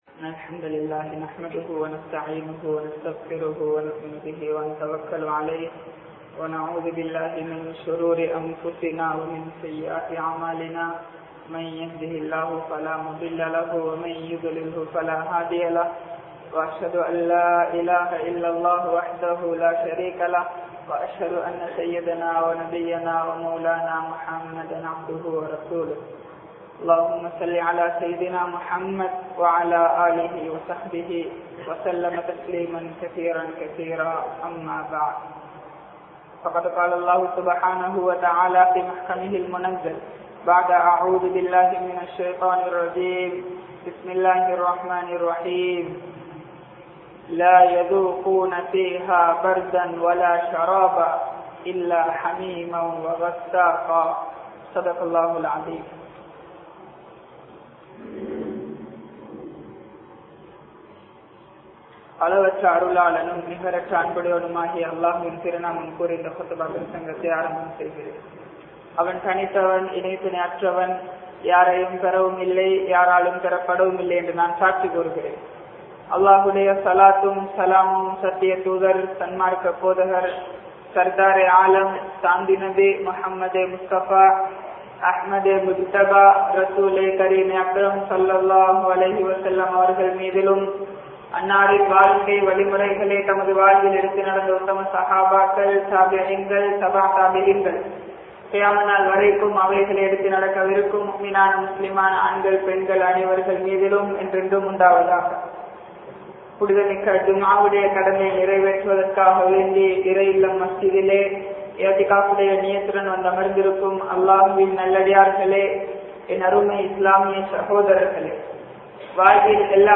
Sutterikkum Naraham (சுட்டெரிக்கும் நரகம்) | Audio Bayans | All Ceylon Muslim Youth Community | Addalaichenai
Majmaulkareeb Jumuah Masjith